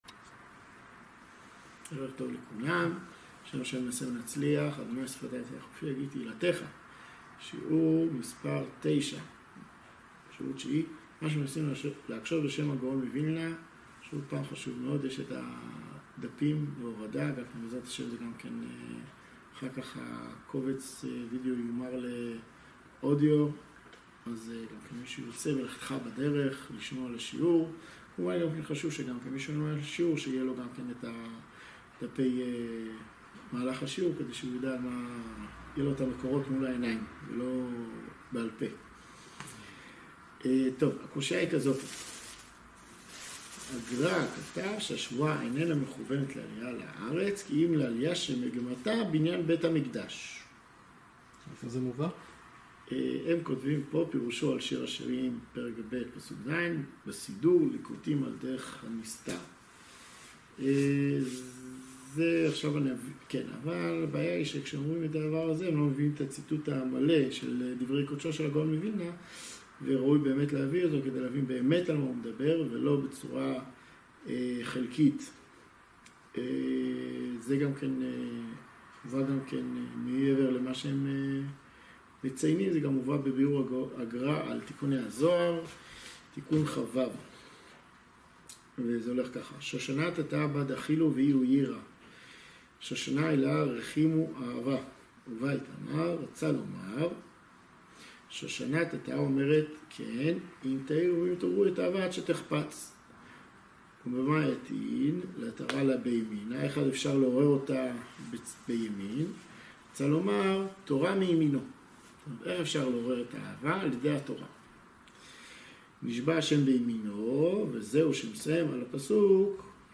שיעור תשיעי - מה שמנסים להקשות בשם הגאון מוילנה